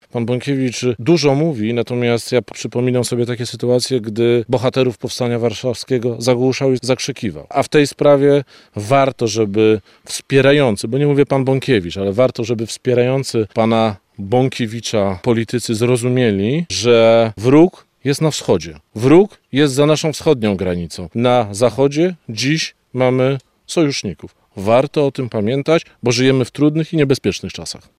- Wróg jest na wschodzie. Na zachodzie dziś mamy sojuszników - mówił we Włodawie minister spraw wewnętrznych i administracji Marcin Kierwiński.